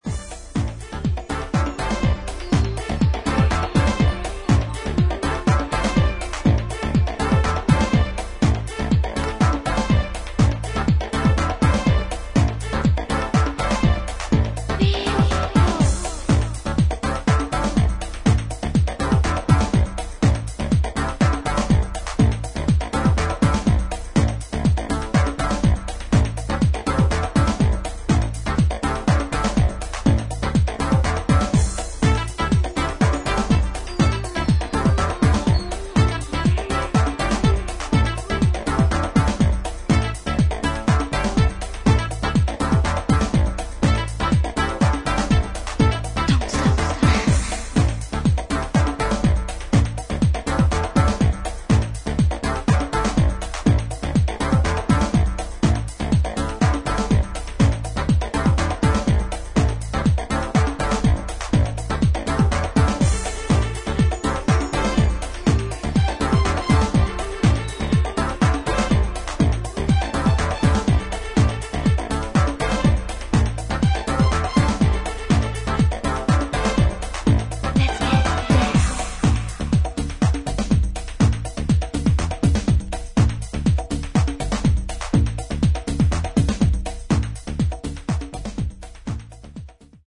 シンプルな4つ打ちにパーカッションが絡み、グルーヴに乗ったシンセワークやストリングス、ヴォイスサンプルで展開する